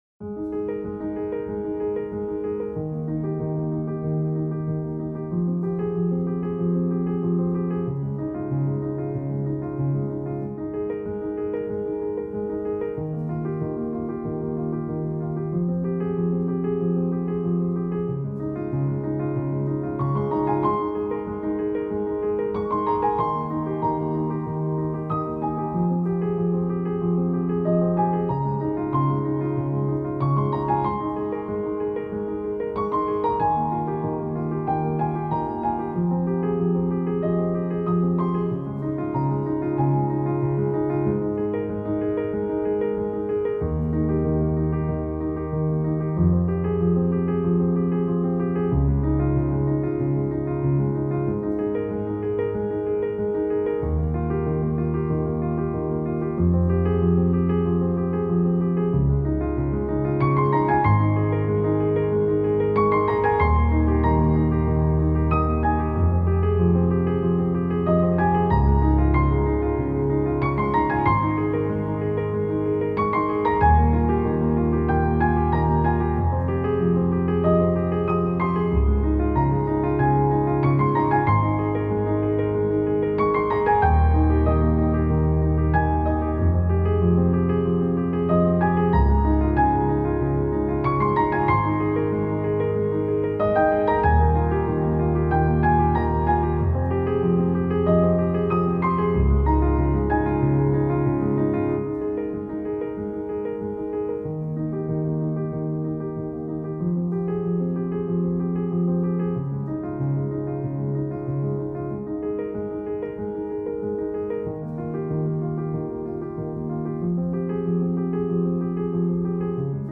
Hörpredigten
Hörgottesdienst_Ostern2026.mp3